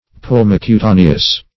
Search Result for " pulmocutaneous" : The Collaborative International Dictionary of English v.0.48: Pulmocutaneous \Pul`mo*cu*ta"ne*ous\, a. [L. pulmo a lung + E. cutaneous.]